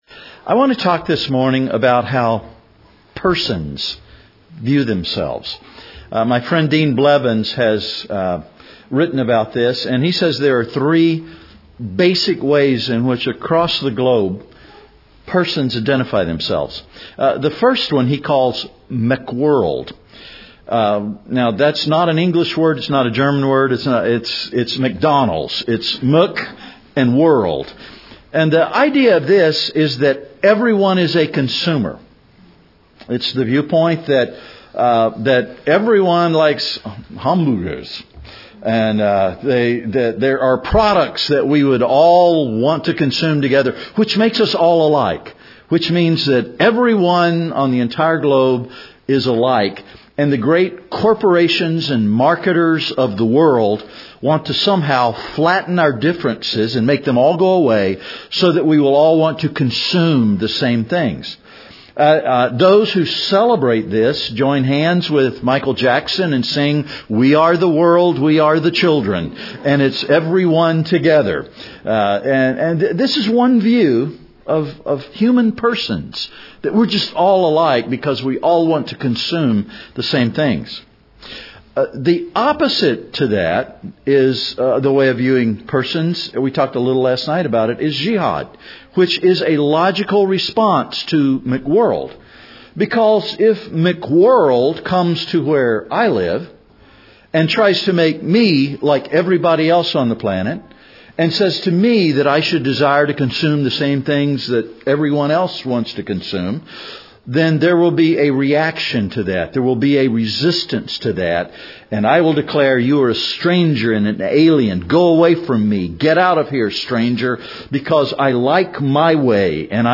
EUNC LC 2013 - Tuesday, 29 January 2013 - Morning Devotional